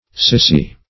Sycee \Sy*cee"\, n.